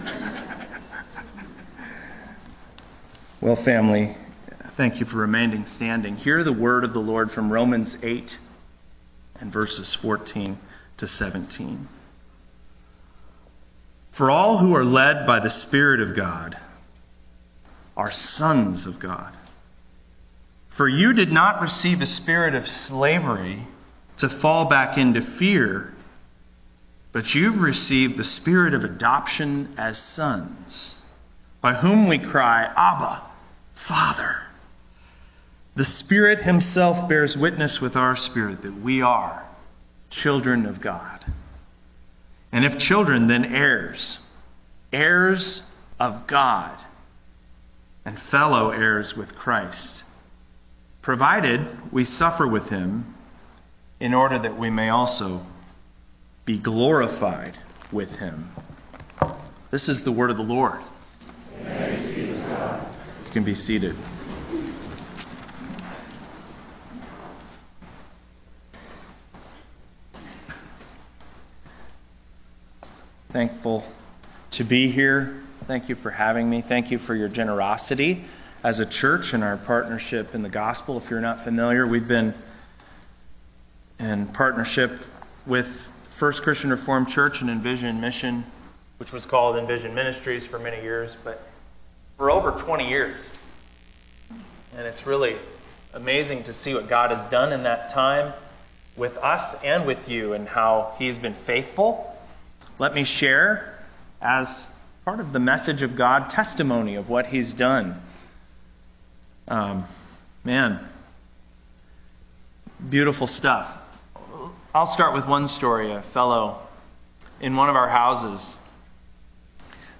Text for Sermon: Romans 8:14-17